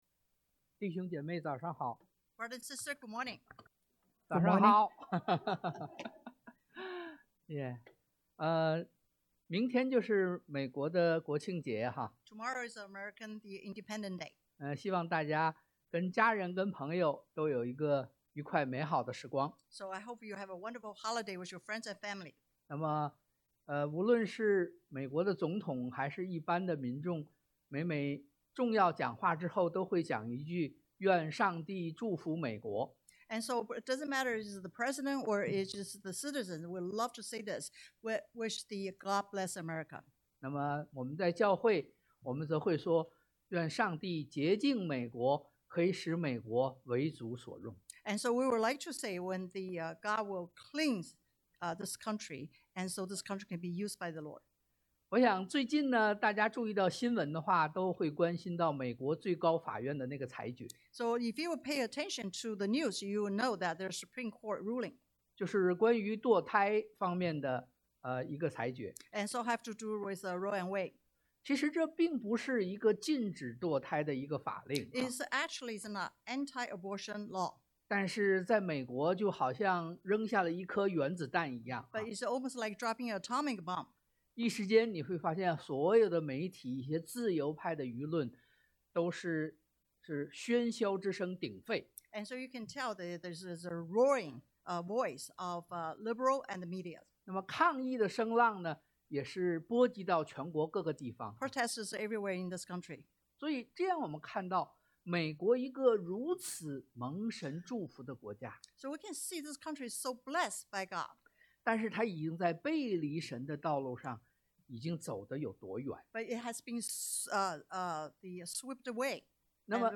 但Dan 1:1-7 Service Type: Sunday AM To set apart but not isolate 分別但不是隔離 2. To engage but not compromise 接觸但不是融合 3. To influence but not confront 影響但不是對抗